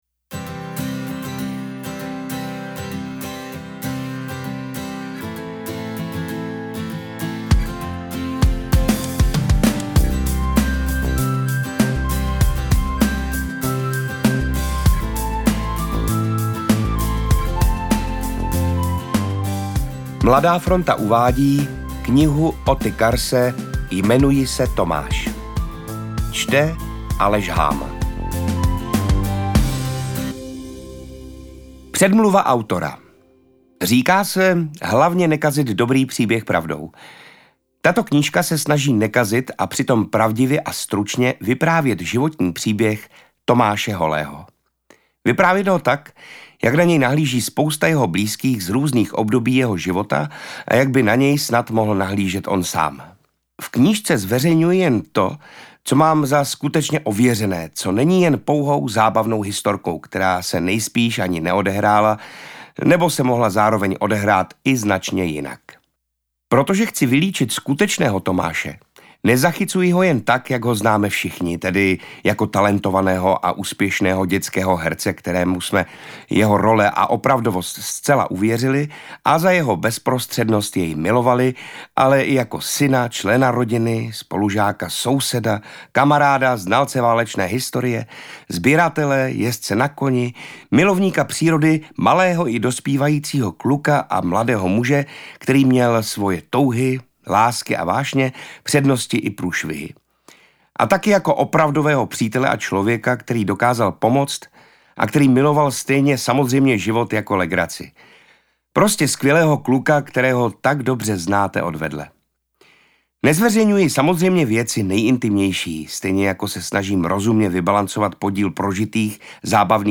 Interpret:  Aleš Háma
AudioKniha ke stažení, 14 x mp3, délka 2 hod. 36 min., velikost 357,6 MB, česky